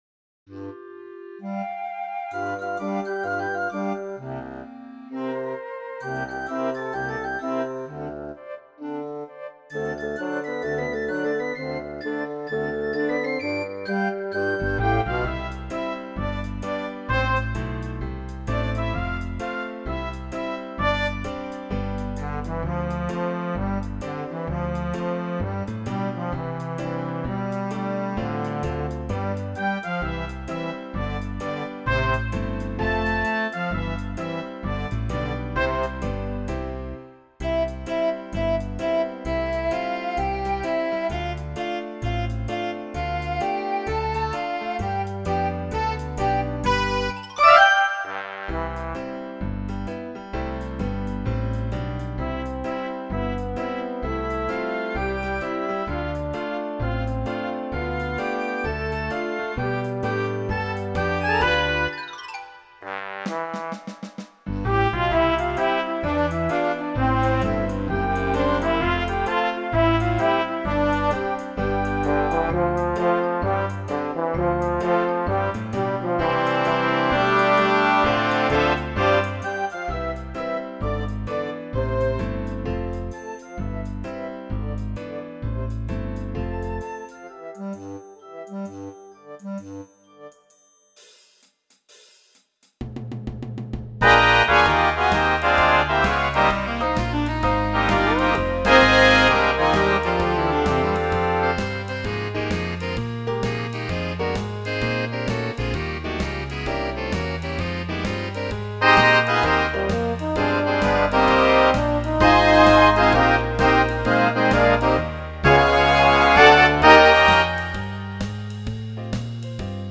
Gattung: Weihnachtstitel
Besetzung: Blasorchester